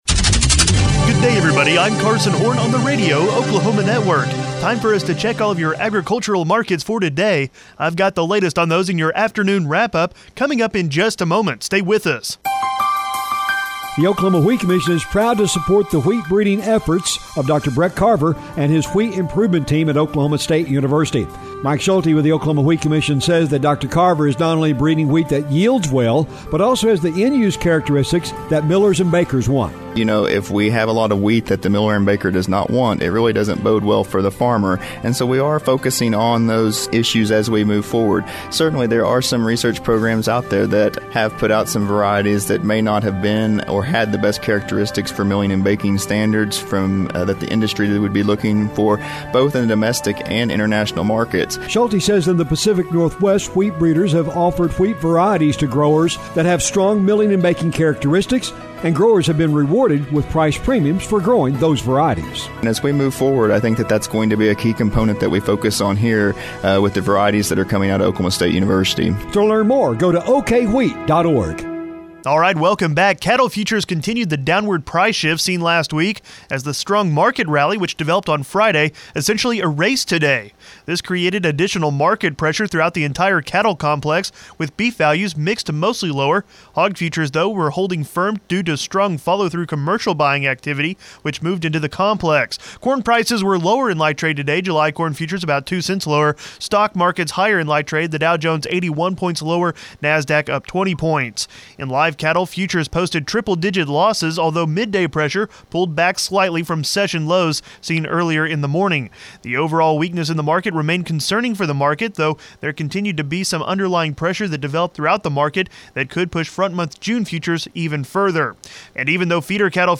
Monday Afternoon Market Wrap-Up